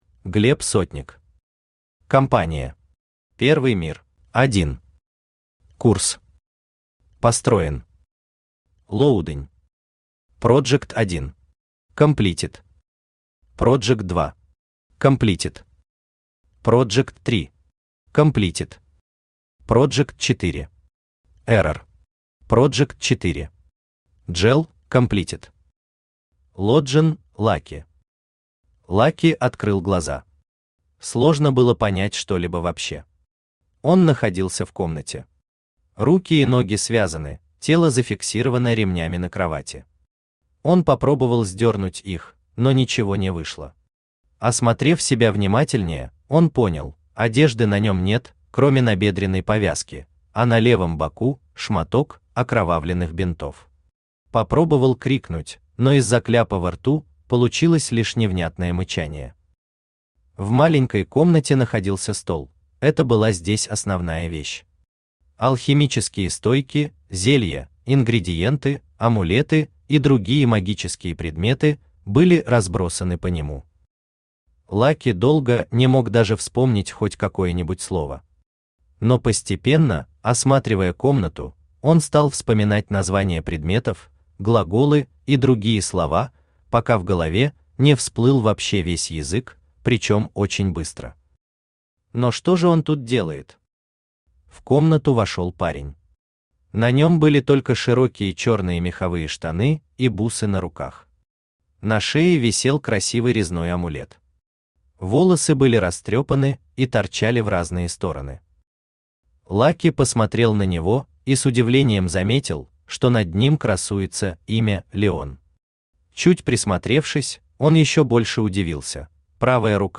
Аудиокнига Кампания. Первый мир | Библиотека аудиокниг
Первый мир Автор Глеб Дмитриевич Сотник Читает аудиокнигу Авточтец ЛитРес.